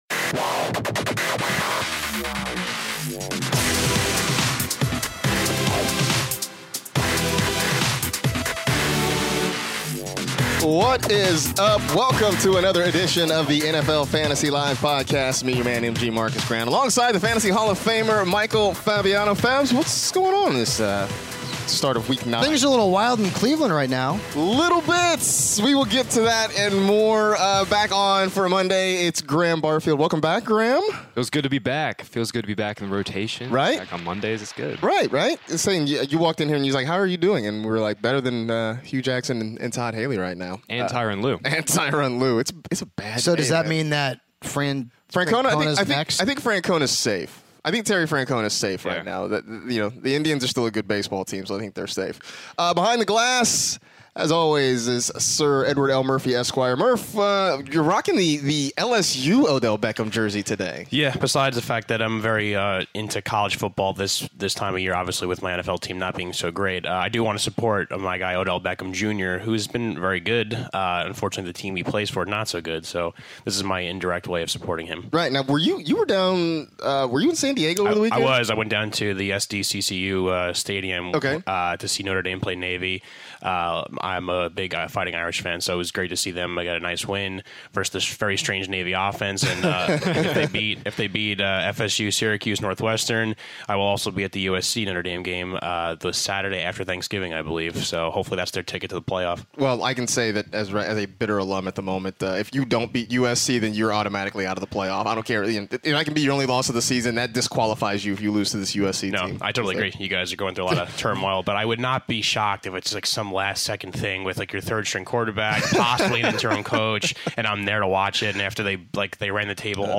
back in studio on a Monday to recap everything fantasy football for Week 8!